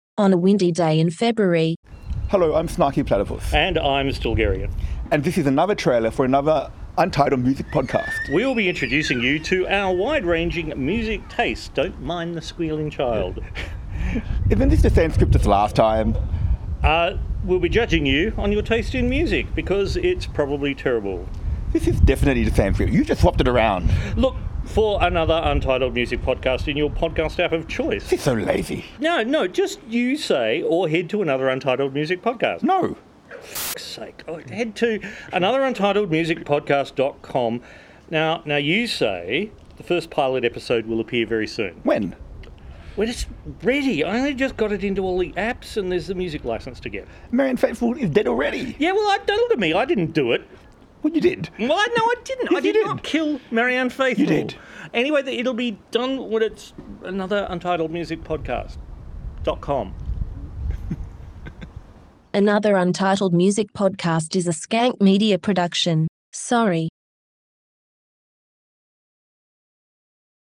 return to that park in North Sydney to record another uninformative trailer for their new podcast. Please ignore the squealing child.